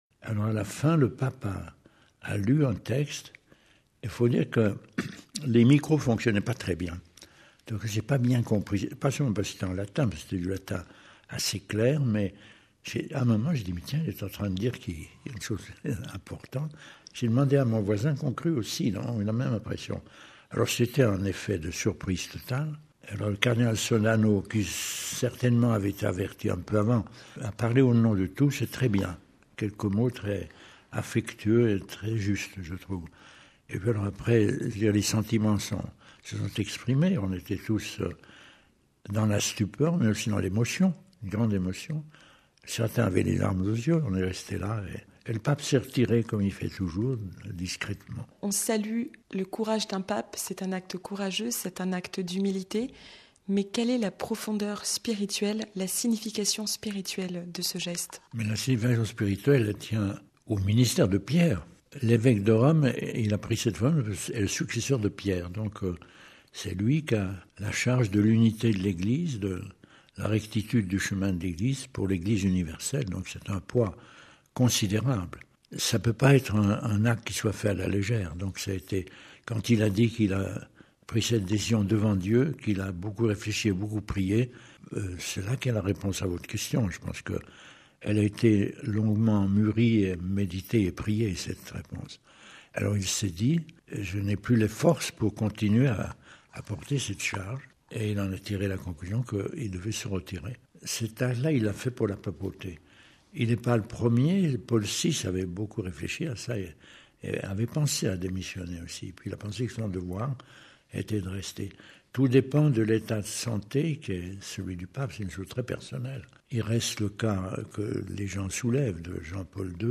Le cardinal Cottier assistait également le 11 février 2013 à la renonciation de Benoît XVI. Il réagissait à ce choix de vie monastique du pape émérite au micro